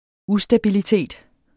Udtale [ ˈusdabiliˌteˀd ]